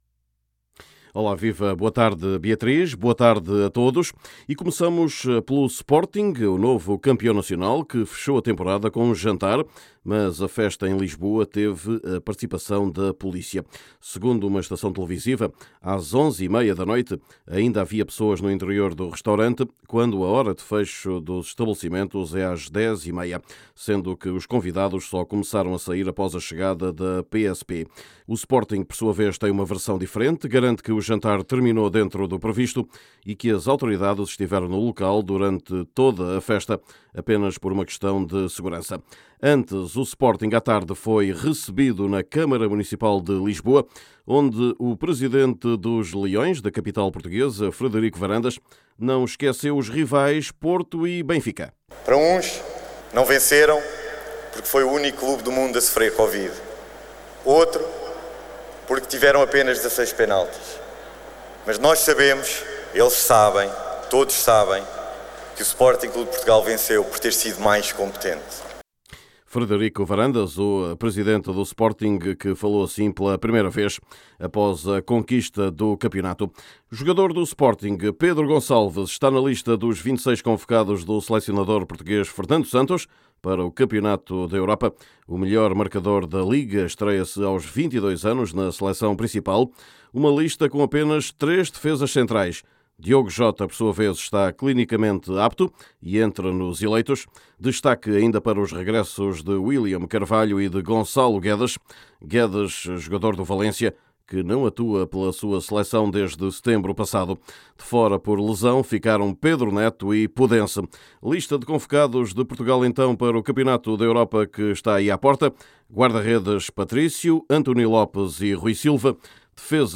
Vamos também escutar o selecionador Fernando Santos, bem como falar das decisões no campeonato português. Também atuais, as investigações ao FC Porto, em face das suspeitas de que um jogador viajou infectado com a Covid-19.